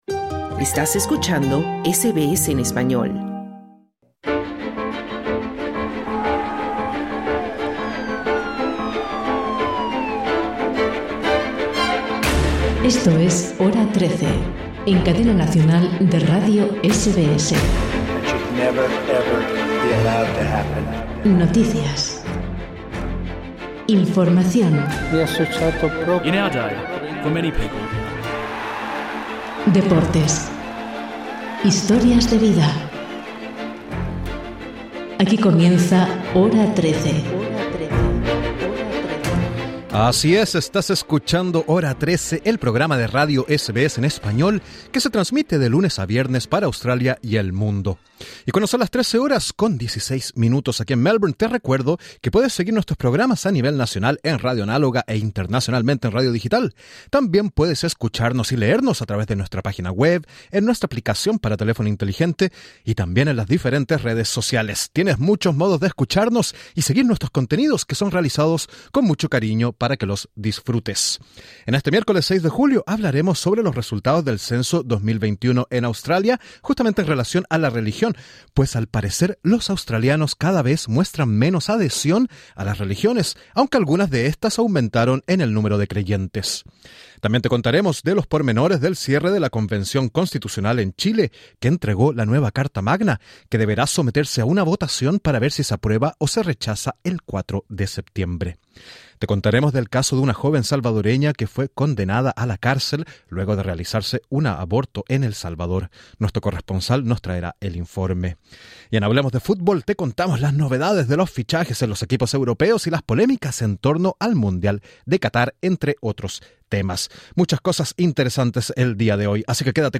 Titulares de la edición del miércoles 6 de julio de 2022: Análisis de los resultados del Censo 2021 en Australia con enfoque en los datos que arrojó esta medición respecto a la religión y creencias de la población. Entrevista con un experto sobre los pormenores de la nueva constitución de la Convención Constitucional en Chile que ahora será sometida a un plebiscito el 4 de septiembre.